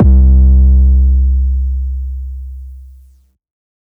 808_Oneshot_Ring_C
808_Oneshot_Ring_C.wav